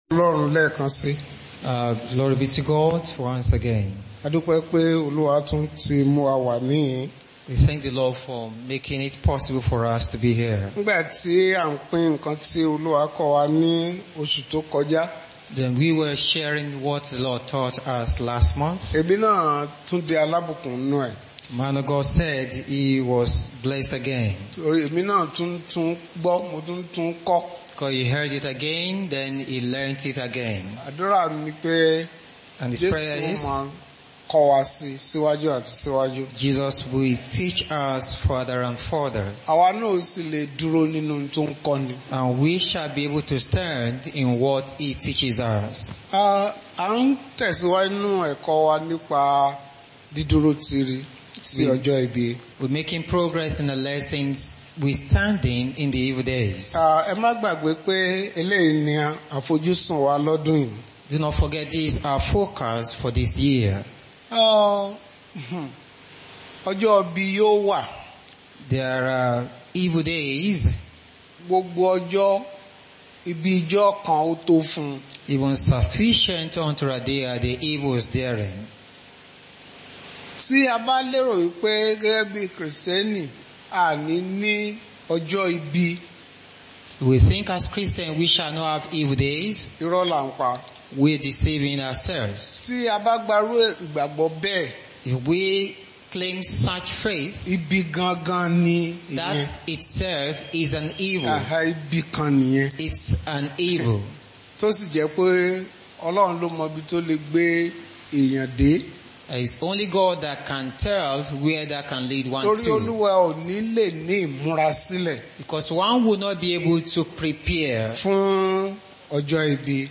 Bible Class Passage: Ephesians 6:18